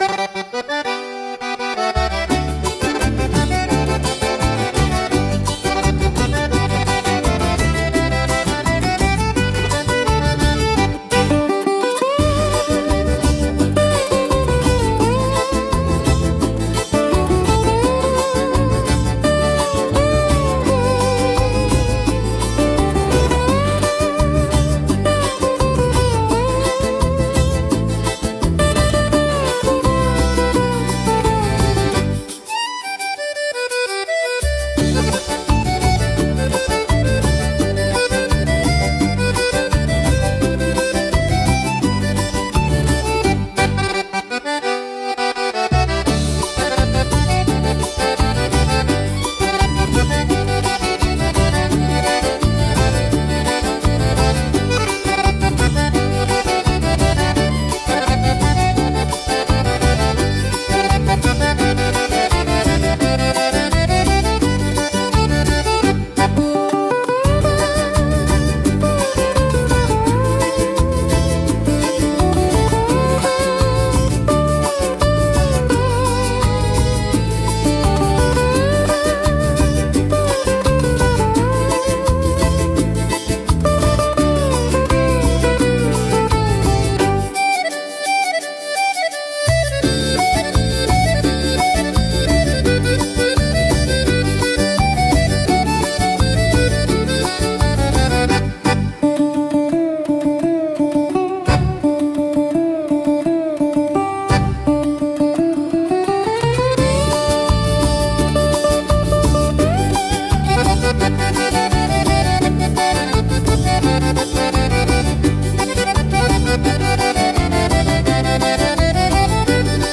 instrumental 7